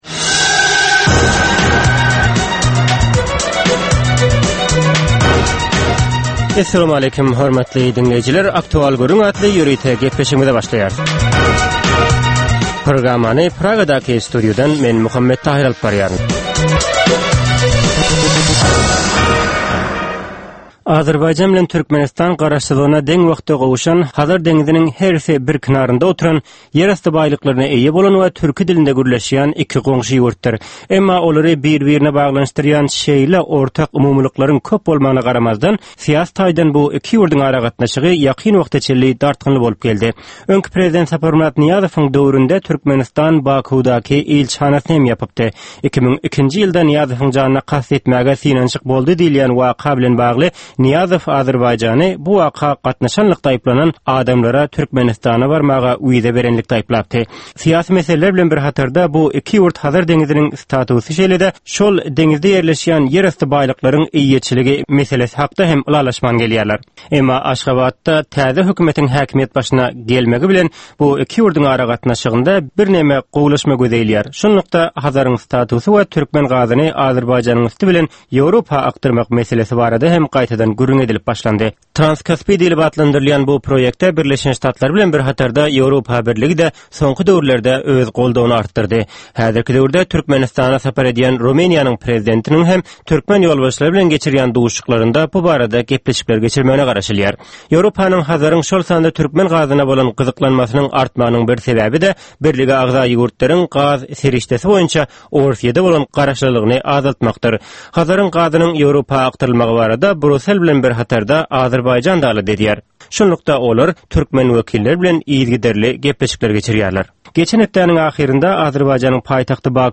Hepdänin dowamynda Türkmenistanda ýa-da halkara arenasynda ýüze çykan, bolup geçen möhüm wakalar, meseleler barada anyk bir bilermen ýa-da synçy bilen geçirilýän 10 minutlyk ýörite söhbetdeslik. Bu söhbetdeslikde anyk bir waka ýa-da mesele barada synçy ýa-da bilermen bilen aktual gürründeslik geçirilýär we meselänin dürli ugurlary barada pikir alsylýar.